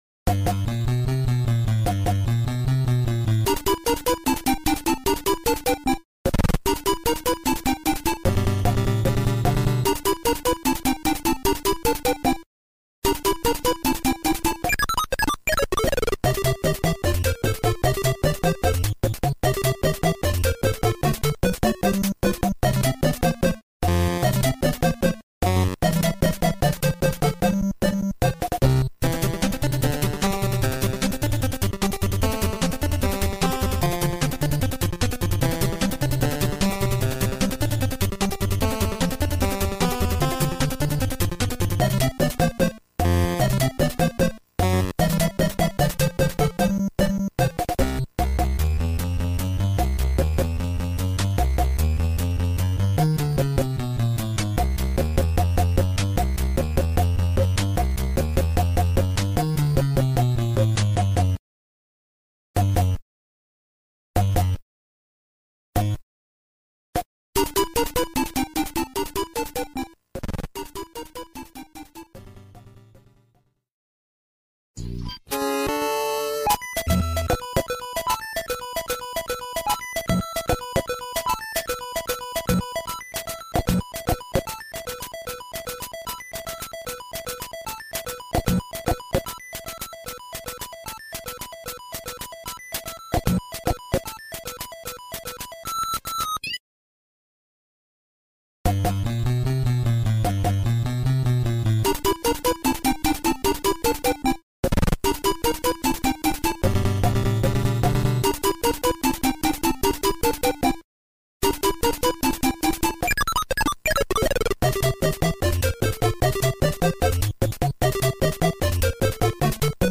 背景音效.MP3